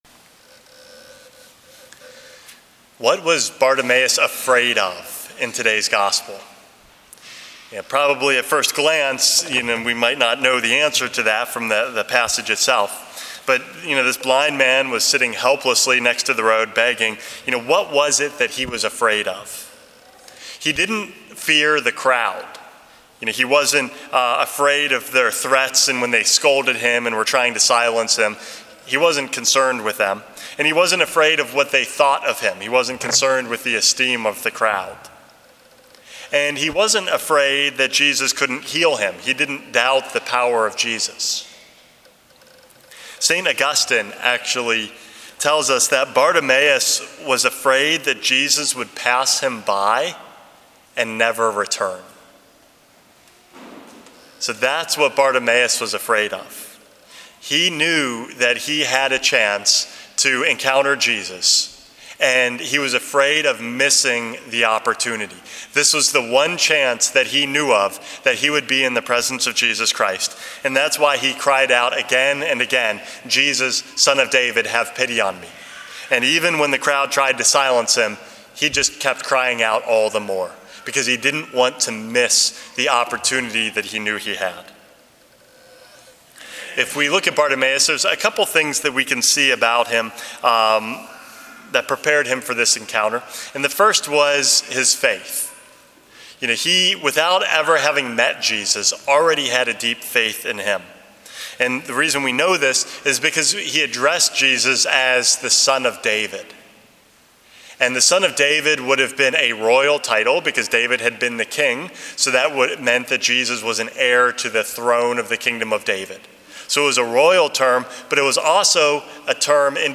Homily #420 - Don't Miss Him